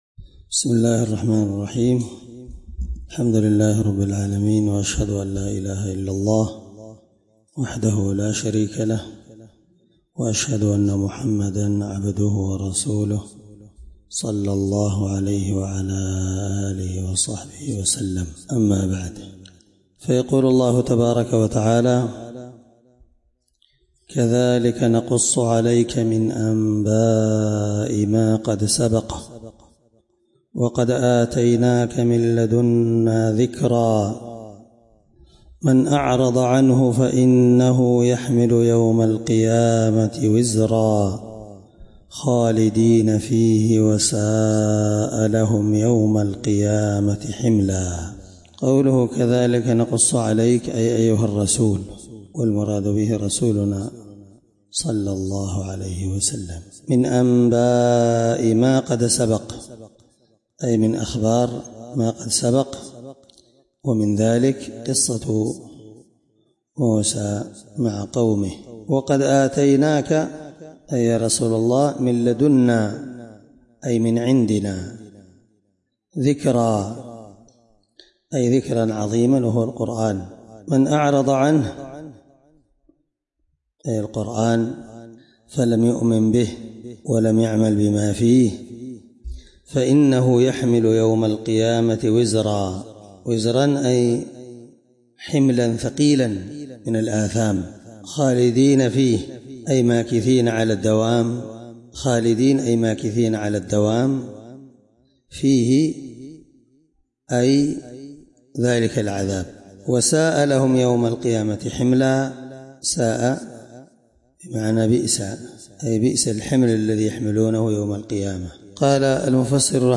الدرس18تفسير آية (99-101) من سورة طه